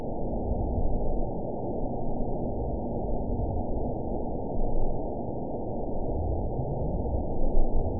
event 911502 date 03/02/22 time 22:45:14 GMT (3 years, 2 months ago) score 6.72 location TSS-AB02 detected by nrw target species NRW annotations +NRW Spectrogram: Frequency (kHz) vs. Time (s) audio not available .wav